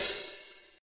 drum-hitfinish.ogg